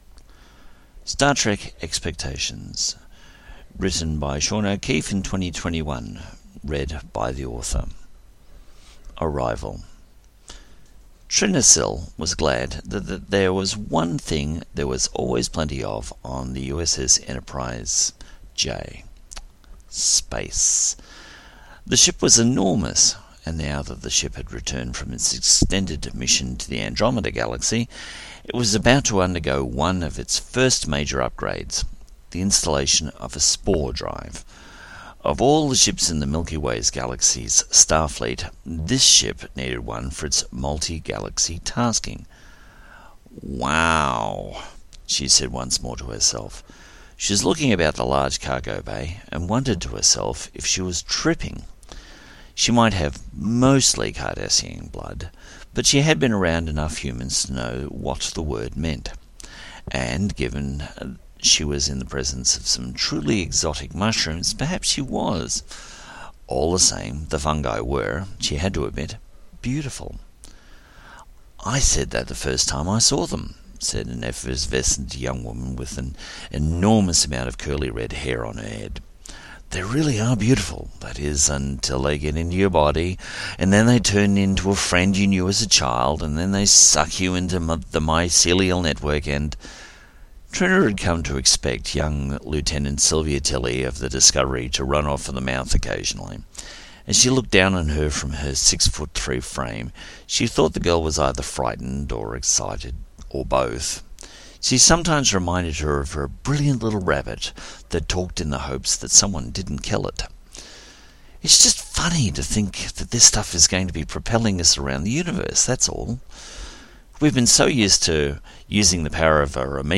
Audio Books/Drama Author(s